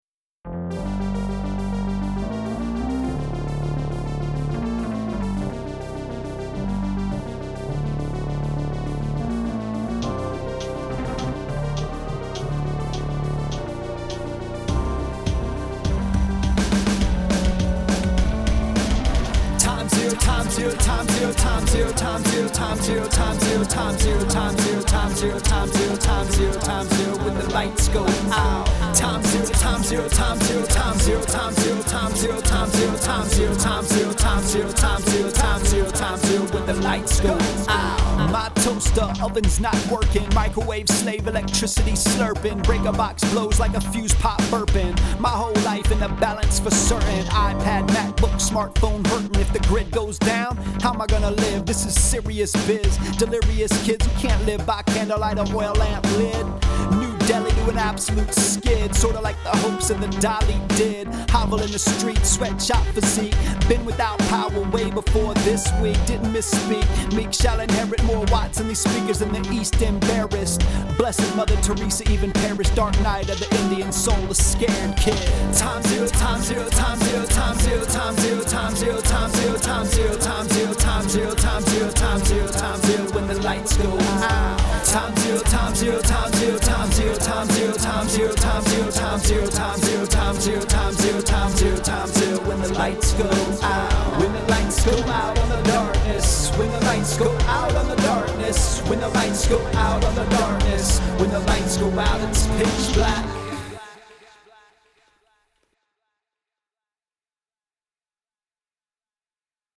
Today’s song blog here: